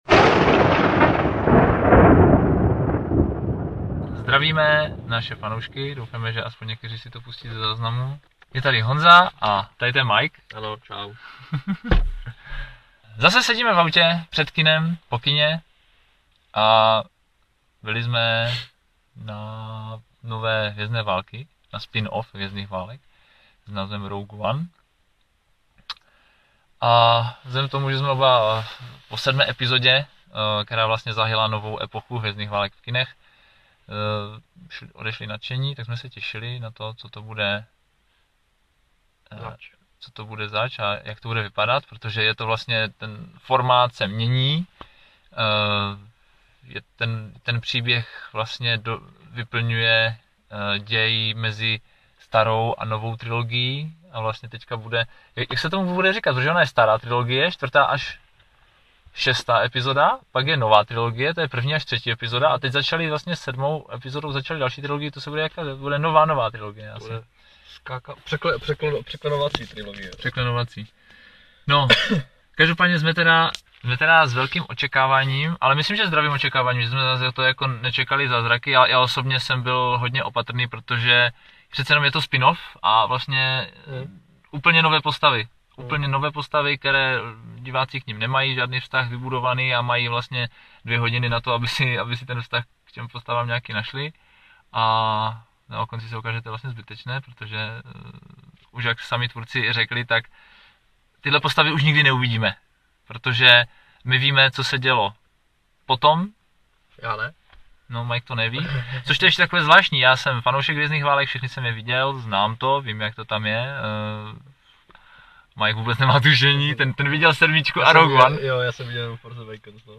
I tentokrát jsme se rozhodli své hodnocení poslat do světa živě a ihned po tom, co jsme vyšli z kina.